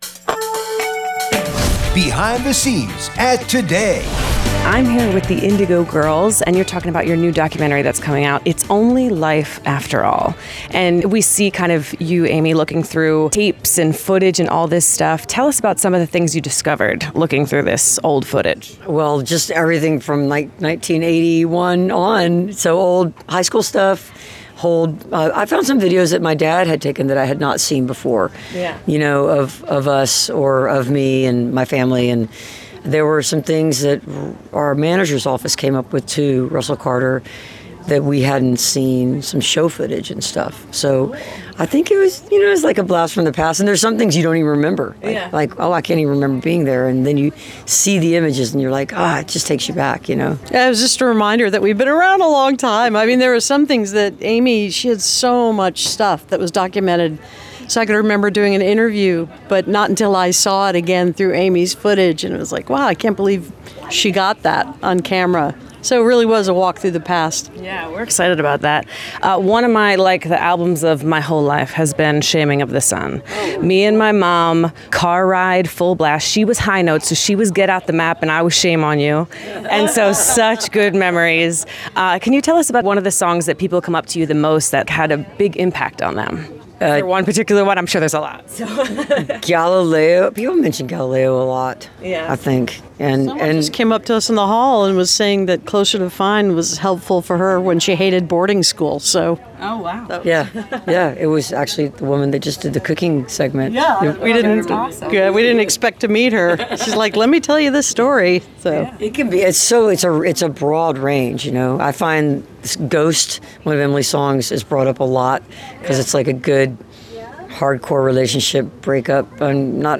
01. interview (4:03)